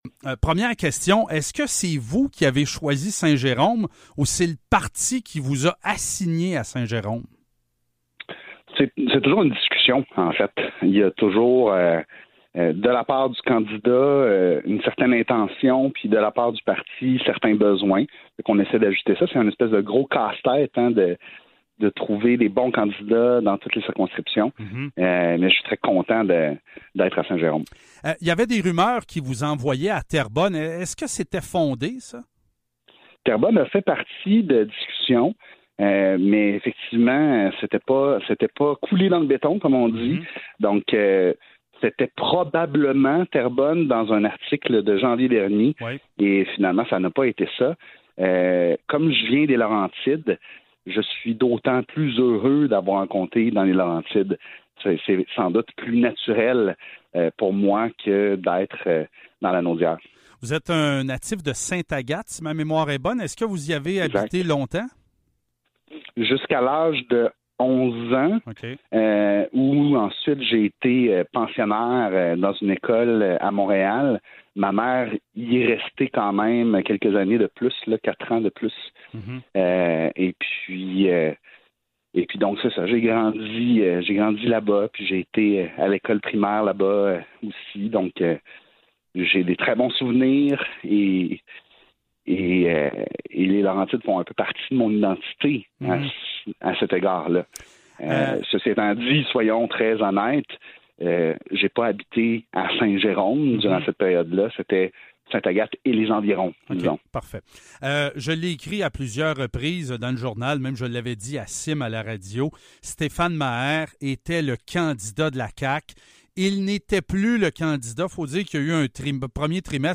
entrevue-youri-chassin-96.mp3